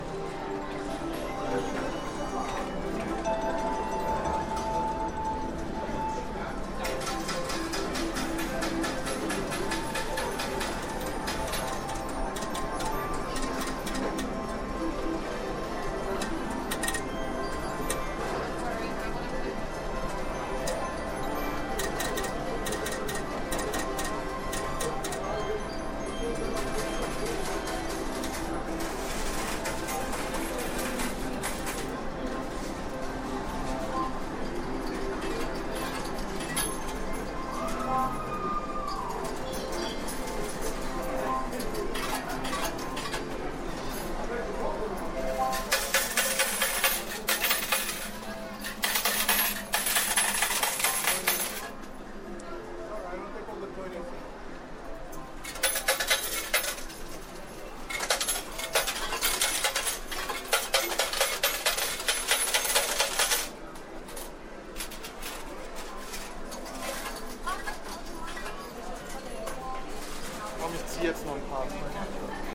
Seaside sounds quiz
To listen to 6 sound clips which can be heard at the seaside and match them to the photos.
Slot machines
slot-machines.mp3